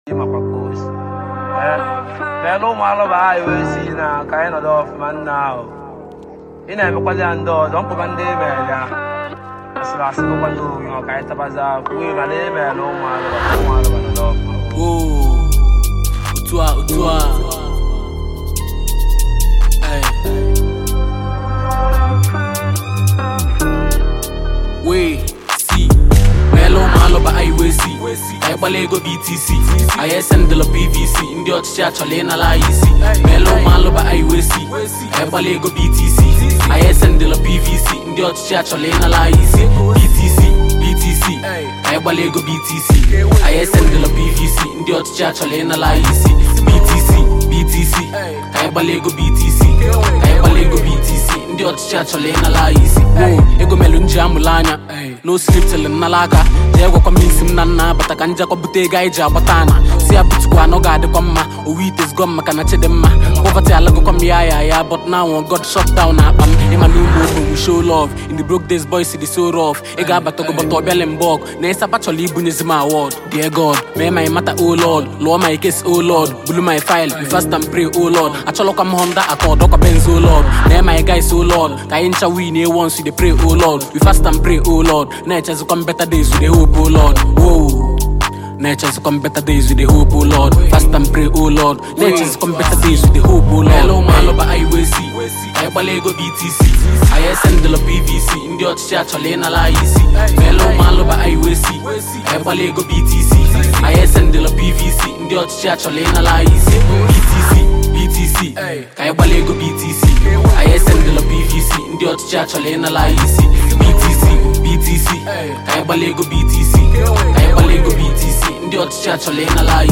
Foreign MusicNaija Music